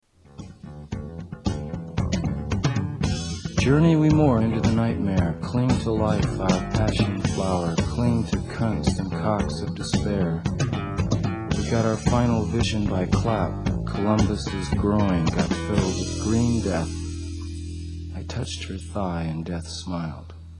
Tags: Jim Morrison quotes The Doors Jim Morrison Poems Jim Morrison spoken word Poetry CD